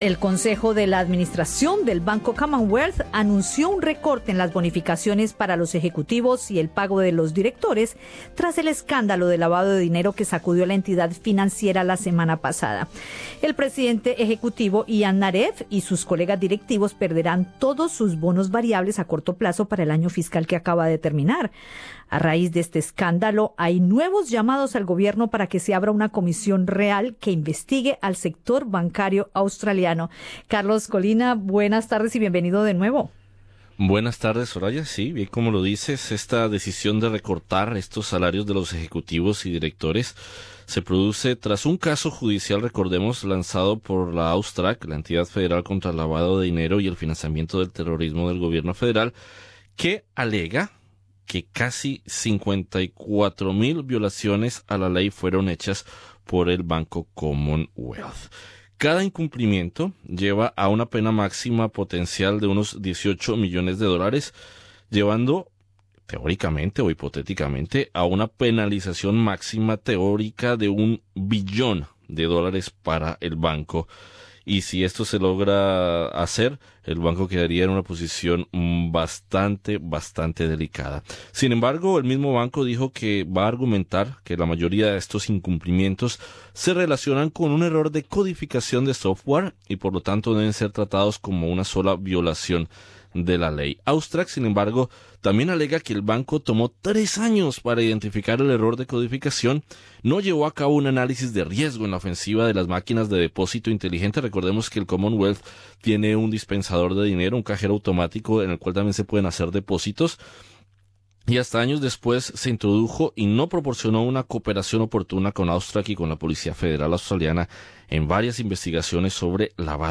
conversa con Radio SBS sobre los efectos de las posibles multimillonarias sanciones al banco